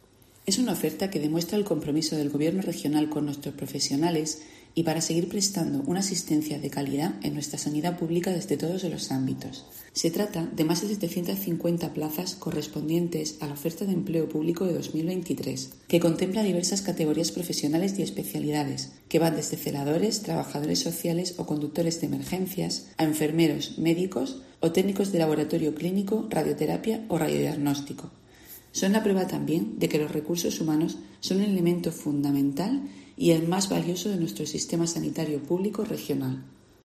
Isabel Ayala, gerente del SMS